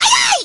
nita_atk_05.ogg